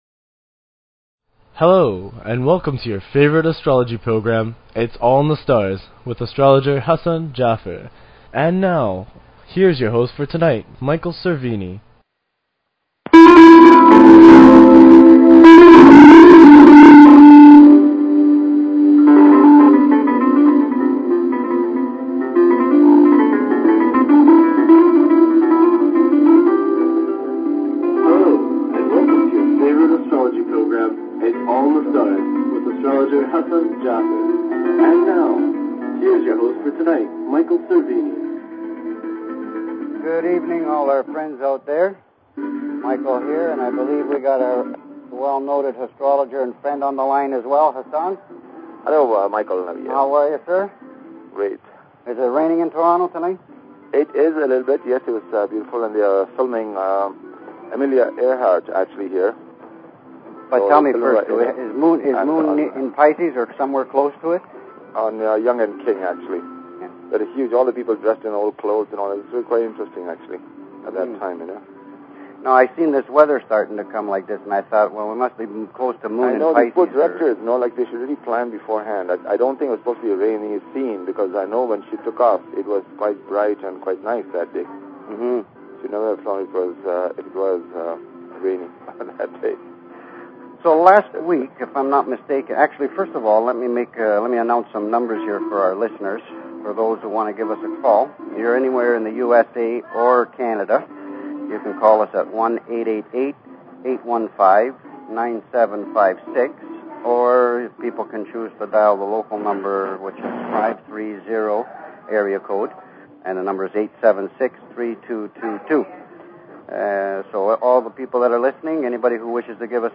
Talk Show Episode, Audio Podcast, Its_all_in_the_Stars and Courtesy of BBS Radio on , show guests , about , categorized as